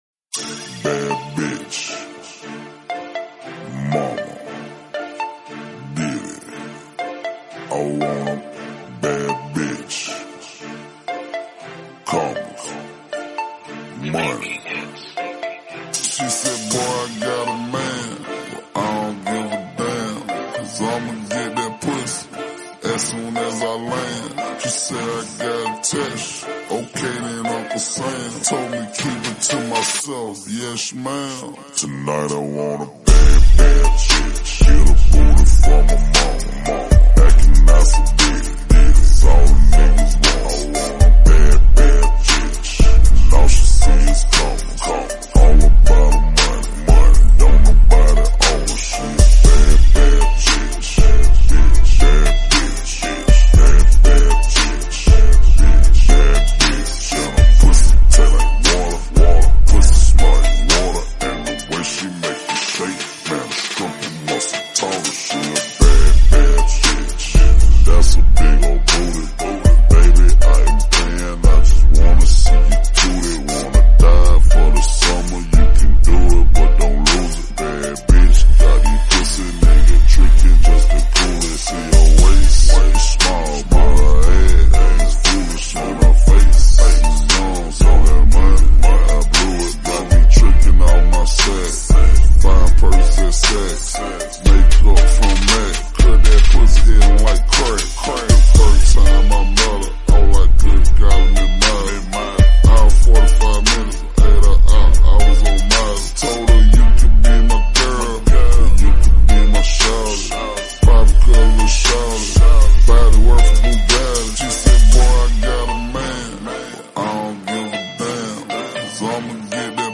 Rebassed Screwed and Low Bass